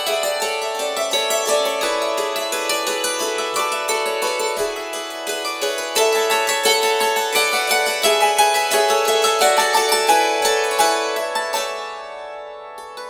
Hackbrettquartett